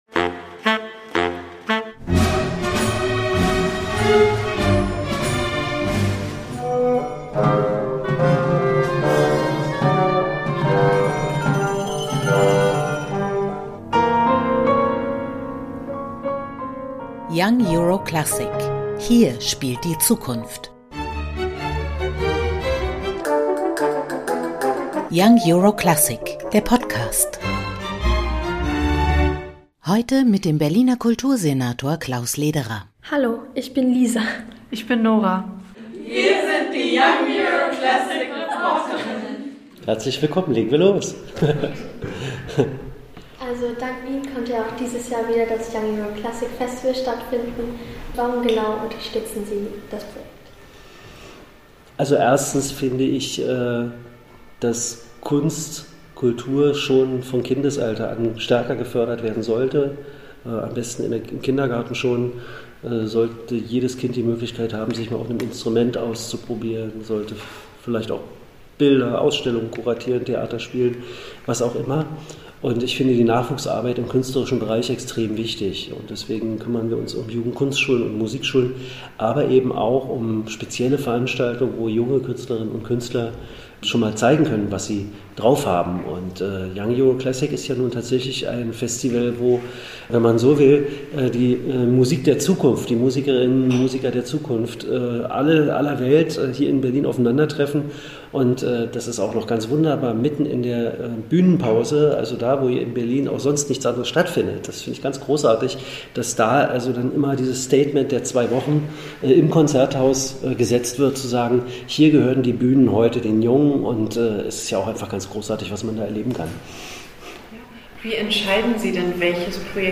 Next Generation 2021: Berlins Kultursenator Klaus Lederer im Interview